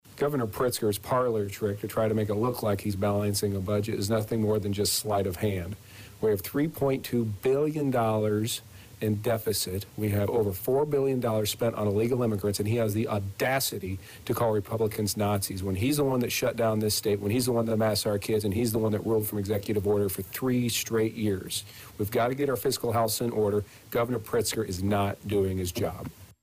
adam-niemerg-on-governors-address.mp3